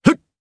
Kibera-Vox_Attack1_jp.wav